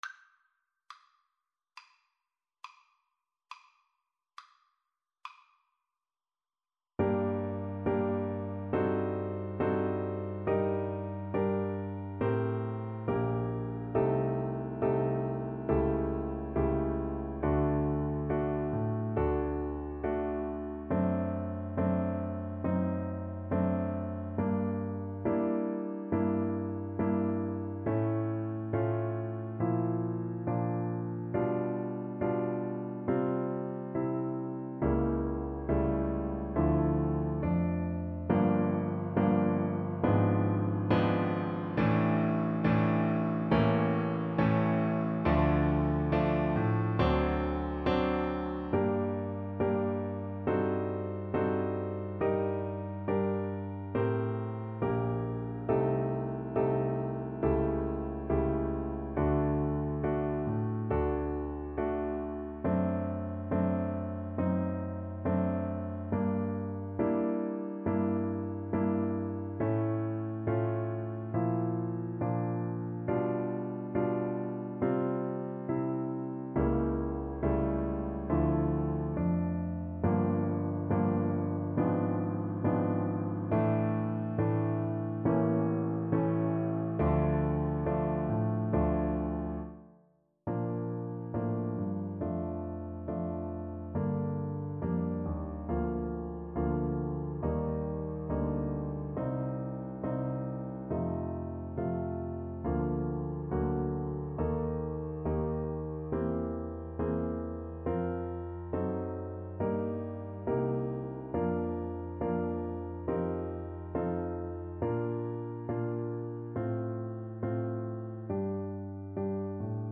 Play (or use space bar on your keyboard) Pause Music Playalong - Piano Accompaniment Playalong Band Accompaniment not yet available transpose reset tempo print settings full screen
G minor (Sounding Pitch) (View more G minor Music for Double Bass )
4/4 (View more 4/4 Music)
Classical (View more Classical Double Bass Music)